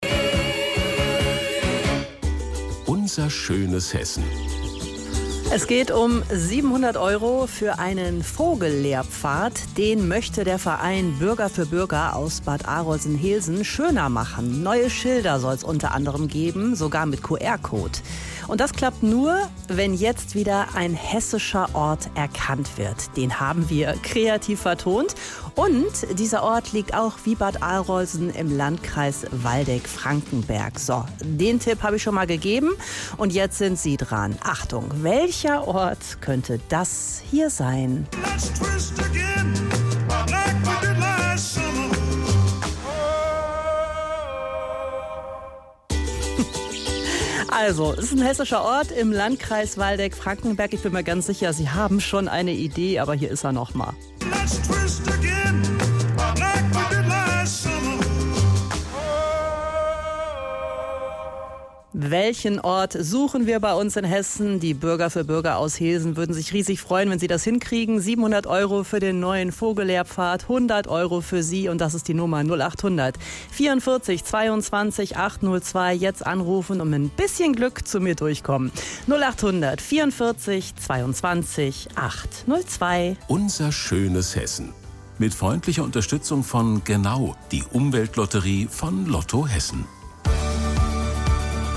Am Dienstag, 22.04. wurde ich interviewt. Ich stellte unsere Planungen zur alten Vogelfahrt und künftigen Vogel- und Naturlehrpfad vor.